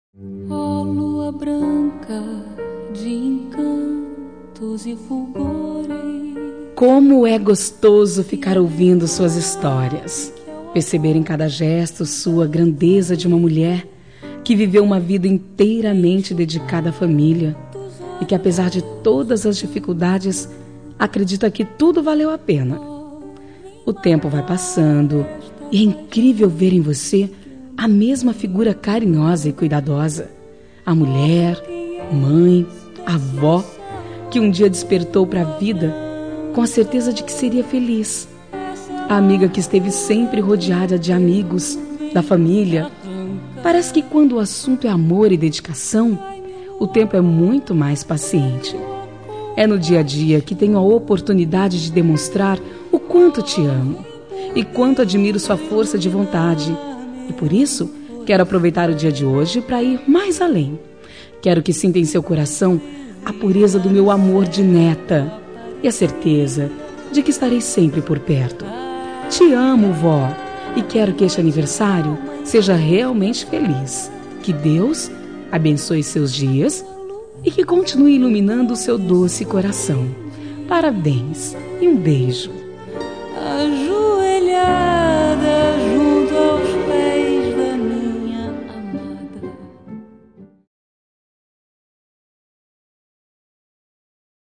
Telemensagem Aniversário de Avó – Voz Feminina – Cód: 2055 – Linda